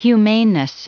Prononciation du mot humaneness en anglais (fichier audio)
Prononciation du mot : humaneness
humaneness.wav